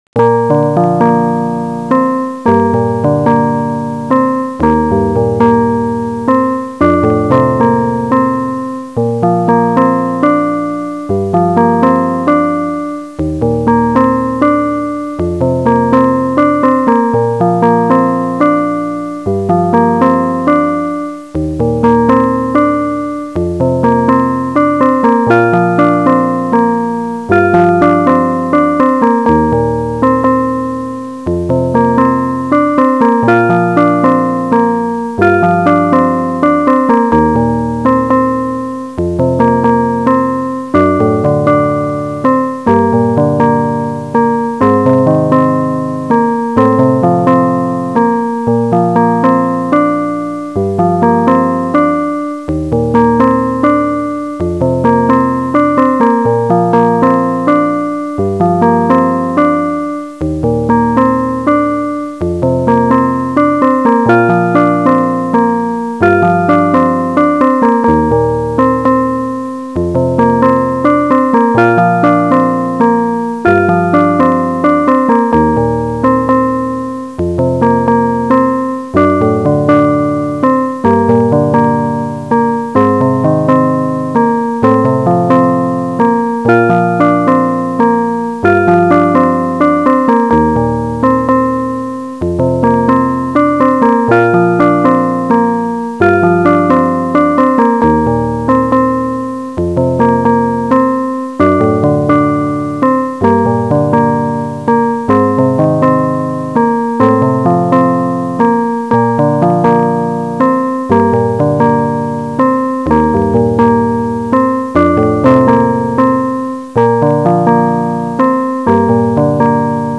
The Remake of the Original ( midi , wav ) -- Correcting all of the problems from the first one, and switching the instrument from an Electric Piano to a Grand Piano.